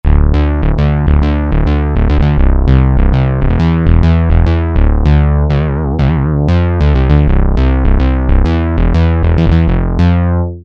E09 - Magnum Bass Gnarly, meaty analog bass